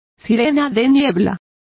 Complete with pronunciation of the translation of foghorn.